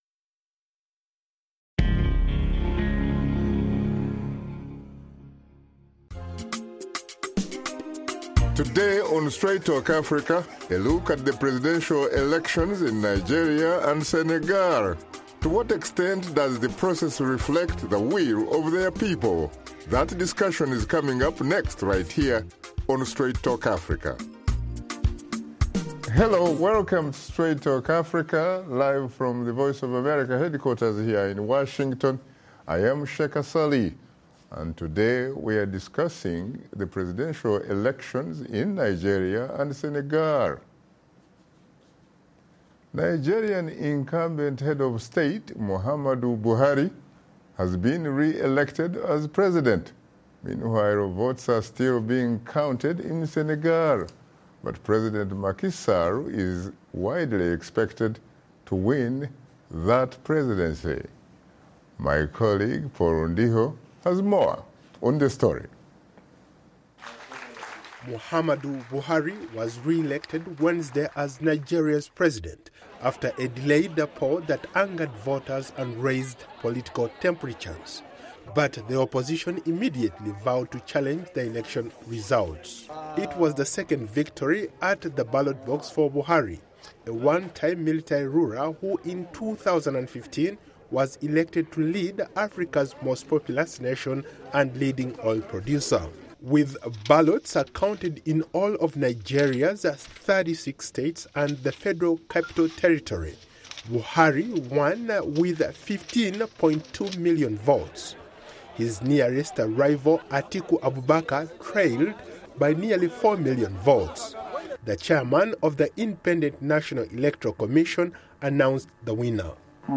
He is joined in studio